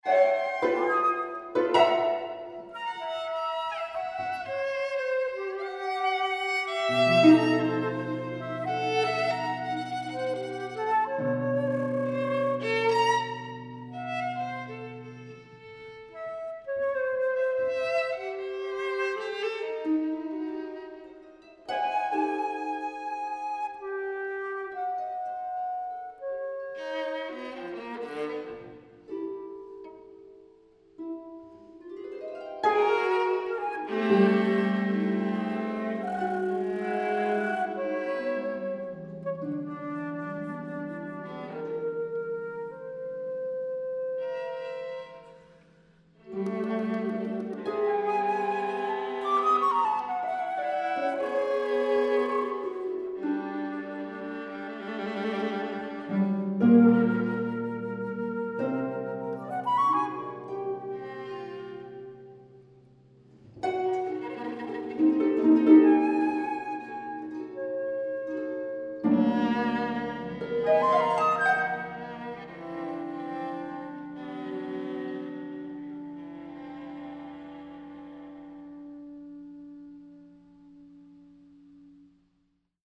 Chamber music
Two Pieces for Piano Trio
Australian, Classical